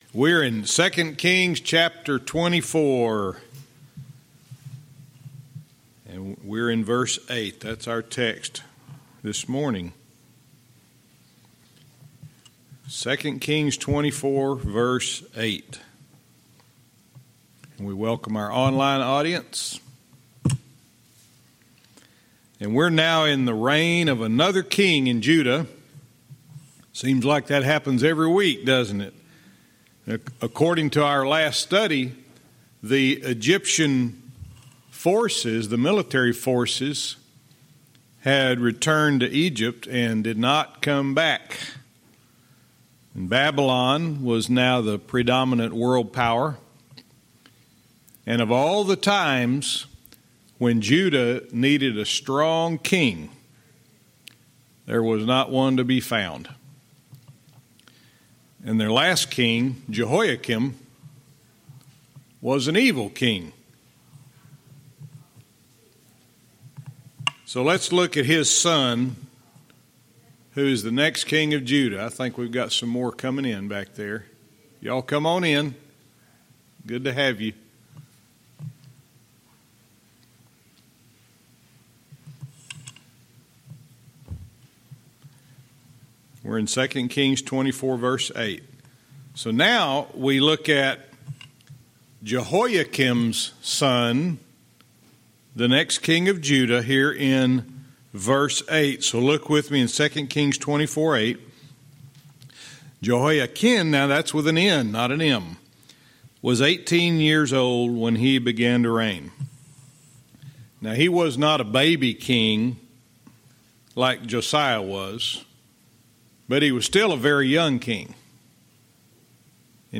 Verse by verse teaching - 2 Kings 24:8-14